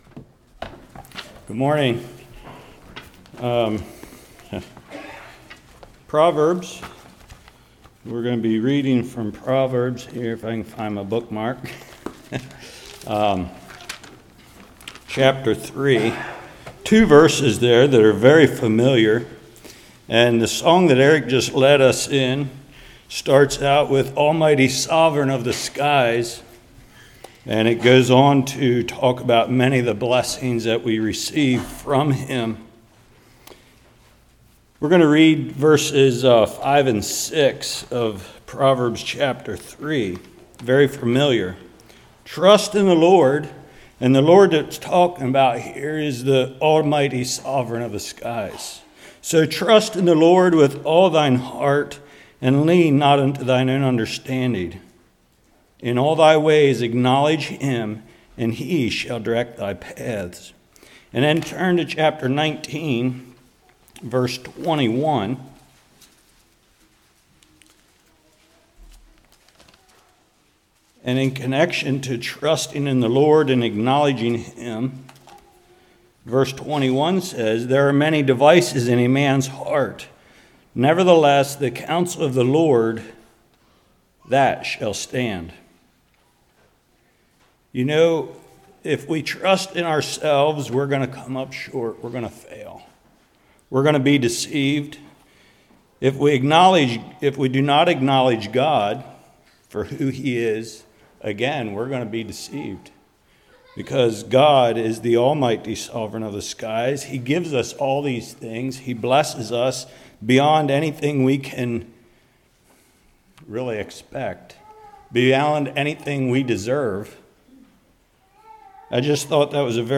19:21 Service Type: Morning Man makes plans based on what he wants